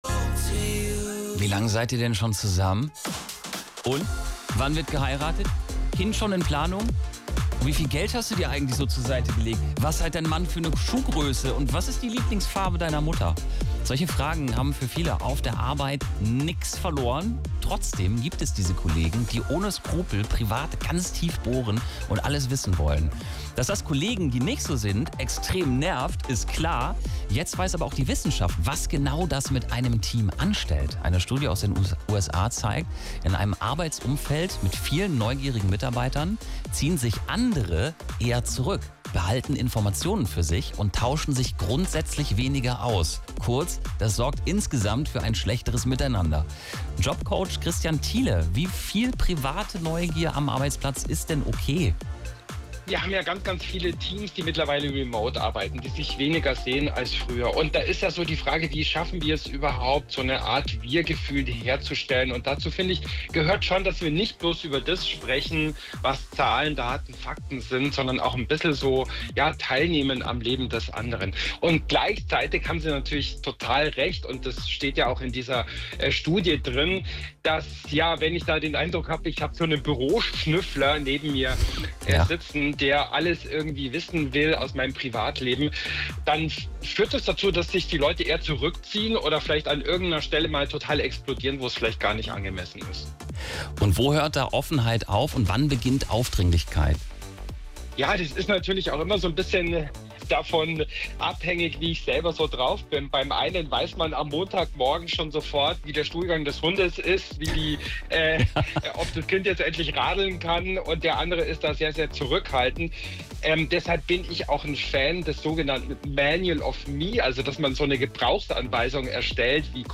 PS: Wenn du auf das SWR-Interview neugierig bist –
hier ist der Mitschnitt!